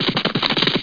00711_Sound_gallop.mp3